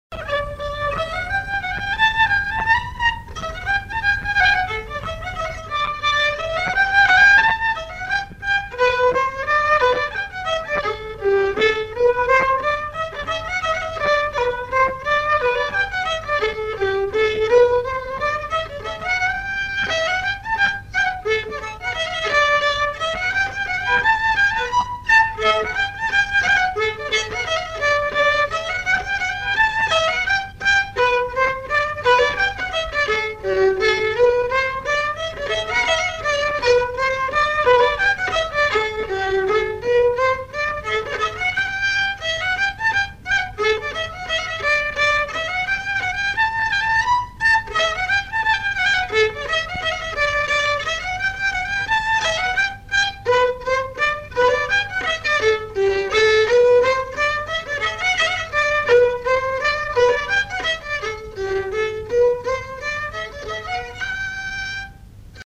danse : scottich trois pas
enregistrements du Répertoire du violoneux
Pièce musicale inédite